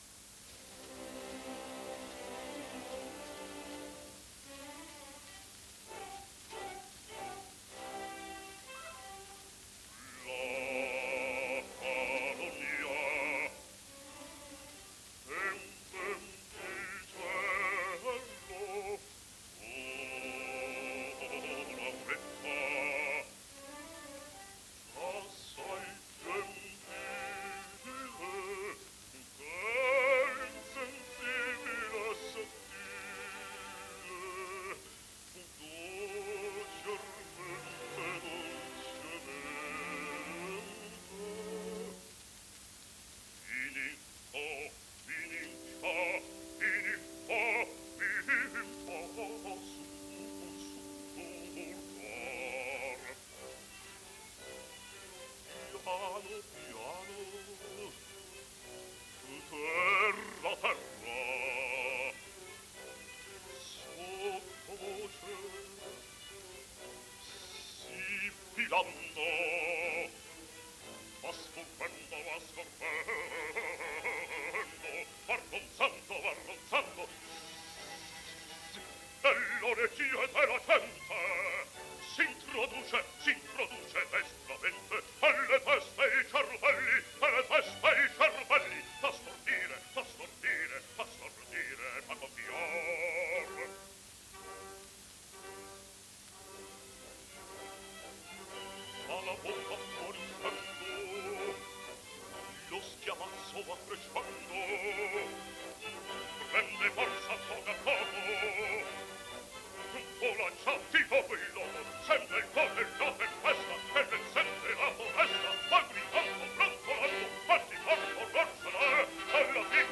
Basso NAZZARENO DE ANGELIS
De Angelis incise moltissimi dischi, sia acustici con la Fonotipia 1905 -1909, sia incisioni elettriche Columbia 1929-1937, di cui sotto ascolterete: